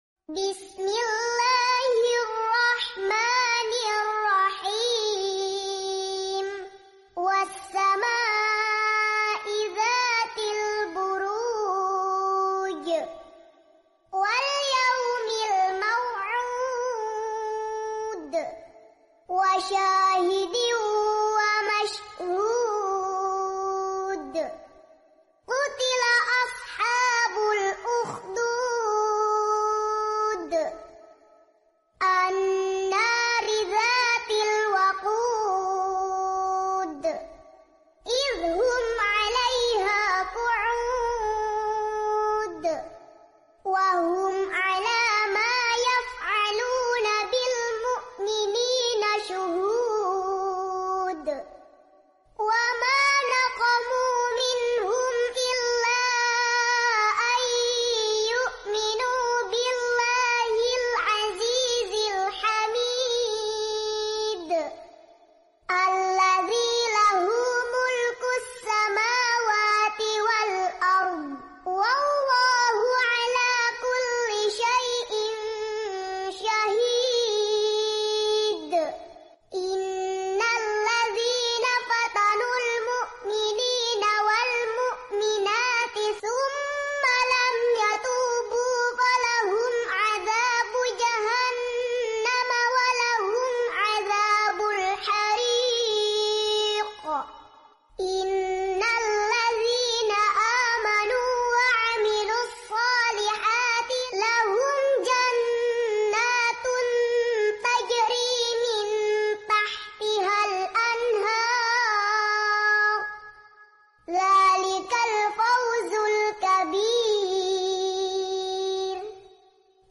Murottal Anak Juz Amma